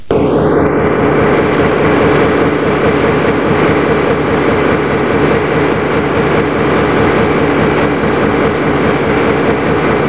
The diesel engine you have heard is the sound of Class Di 3.